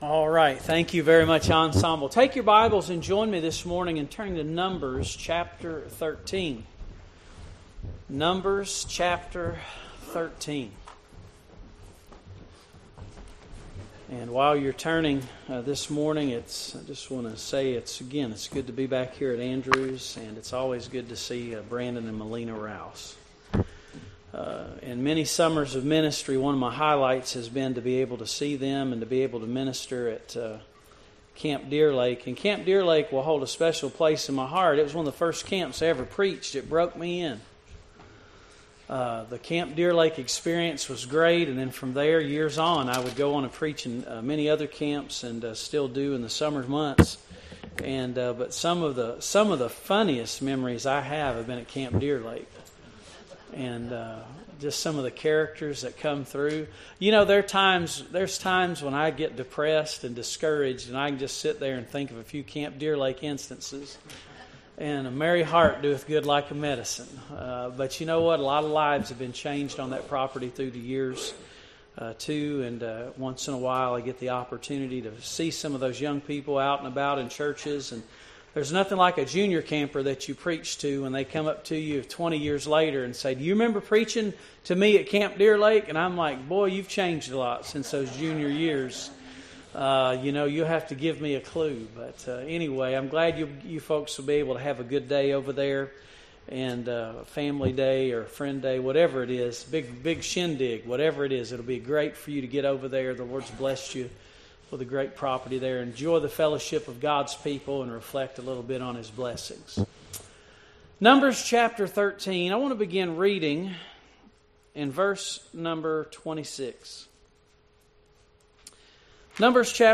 Passage: Numbers 13:26-33 Service Type: Morning Worship